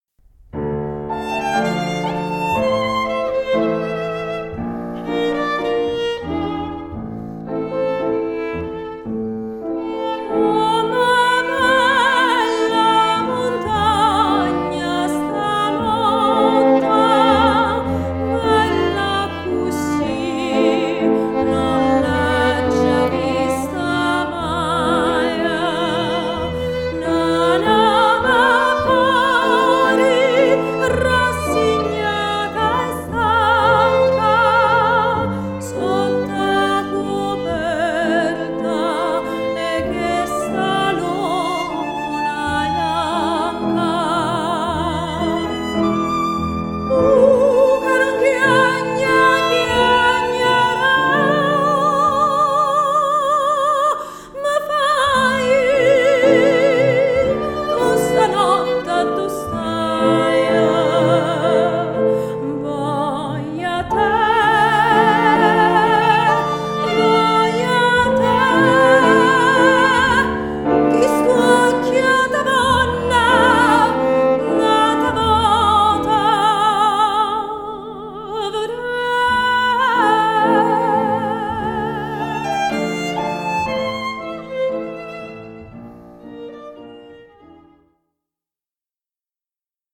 10 Gen 18 soprano Tu ca nun chiagne ok